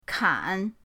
kan3.mp3